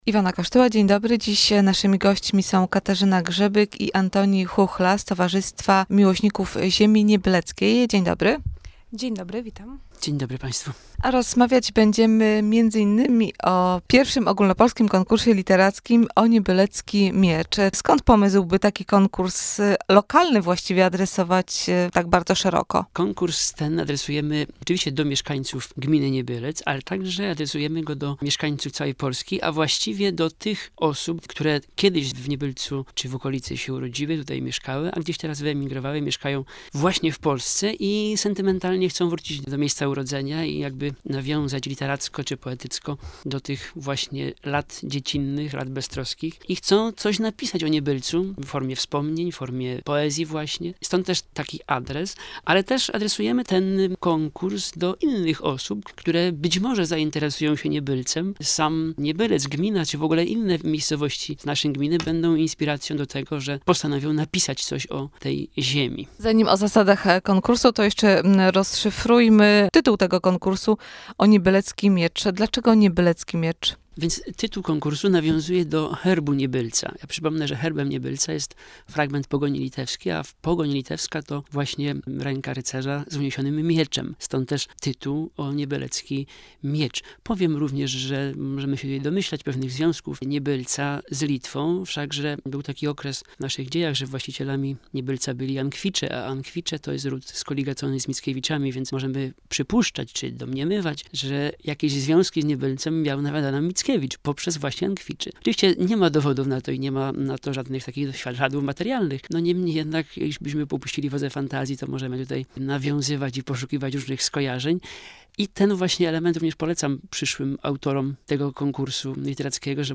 Pobierz plik (2013-12-30.mp3)Nagranie audycji[Magazyn Na Temat - Radio Via]